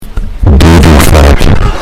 Doo Doo Fart Sound Effect Free Download
Doo Doo Fart